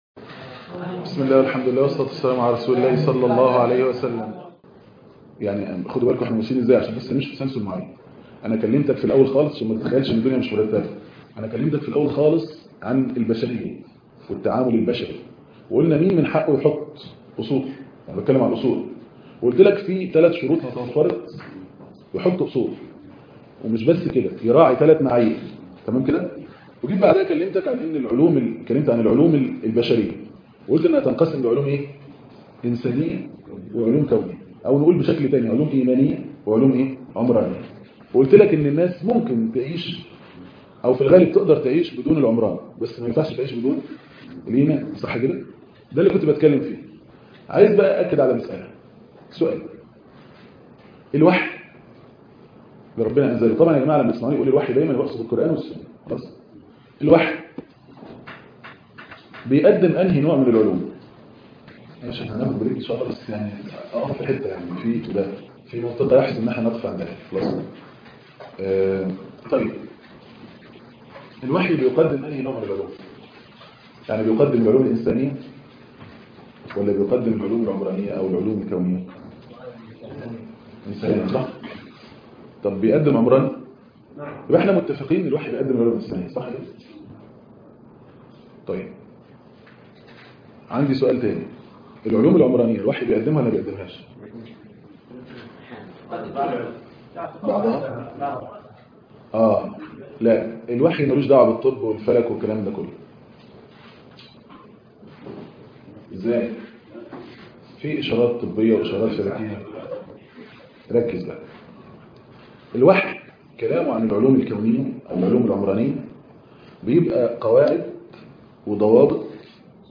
المحاضرة الثانية من الاسبوع الاول في أصول التعامل مع الأطفال - صناعة الطفل القرآني